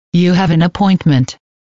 语音提示 " 预约
标签： 声乐 清唱 口语
声道立体声